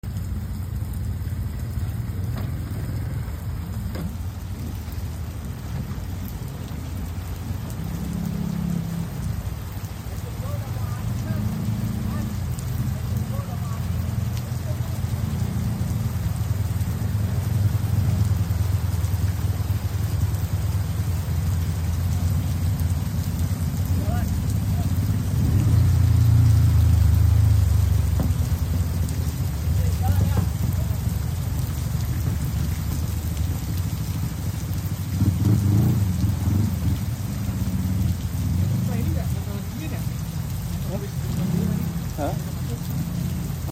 Suasana kampung saat hujan turun